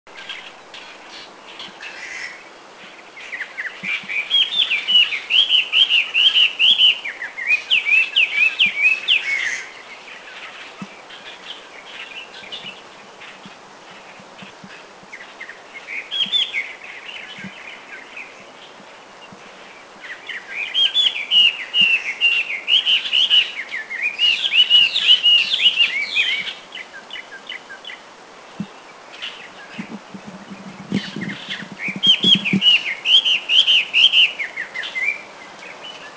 Hwamei 3
[Taiwan Hwamei]    song
Garrulax canorus taewanus
Hwamei3.mp3